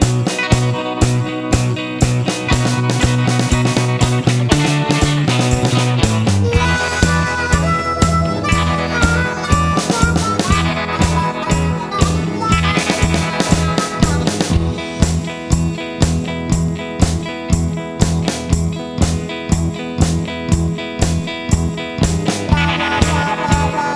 No Harmony Pop (1950s) 2:13 Buy £1.50